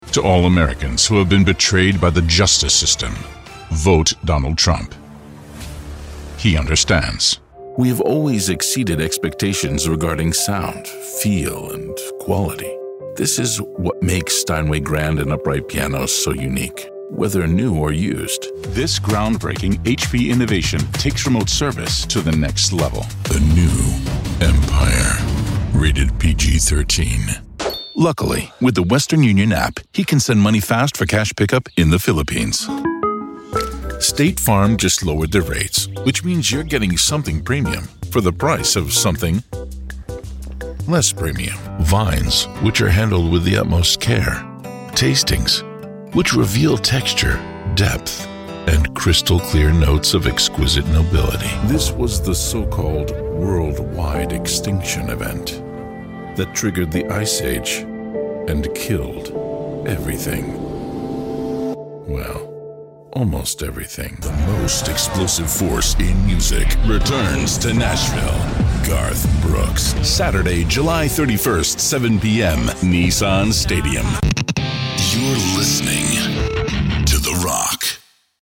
Never any Artificial Voices used, unlike other sites.
Foreign & British Male Voice Over Artists & Actors
English (British)
Adult (30-50) | Older Sound (50+)